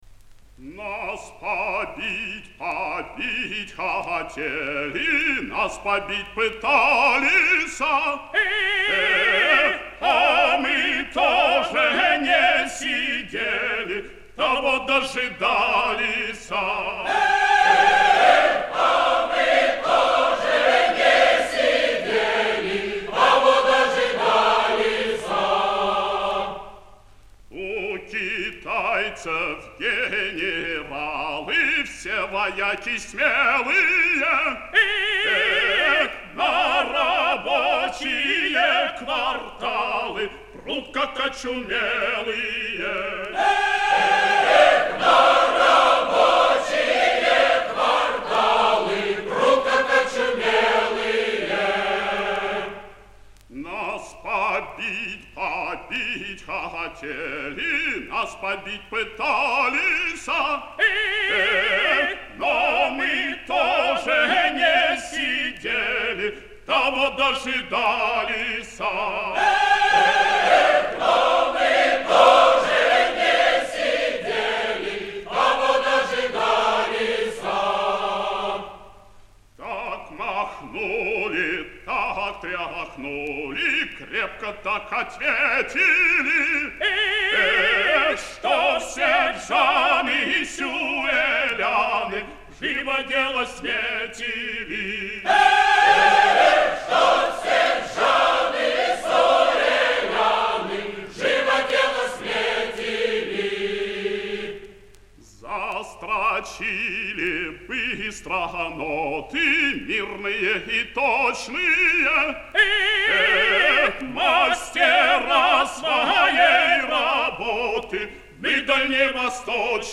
Повышение качества